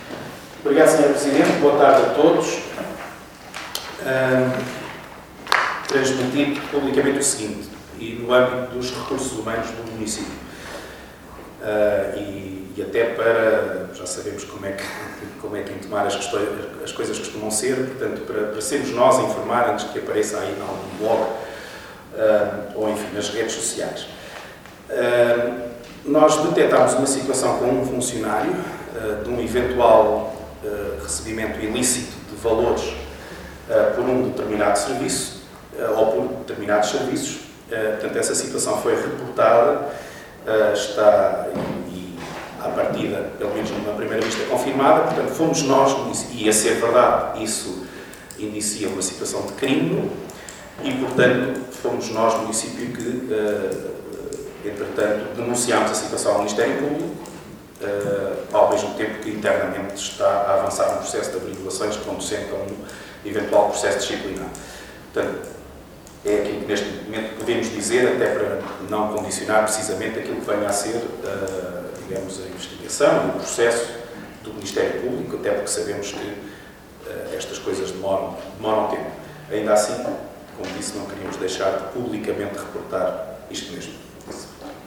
Áudio: Hugo Cristóvão (PS), vereador com o pelouro dos Recursos Humanos, deu conta do caso publicamente, no decorrer da reunião de Câmara Municipal desta segunda-feira, dia 3 de fevereiro. O caso seguiu para o Ministério Público.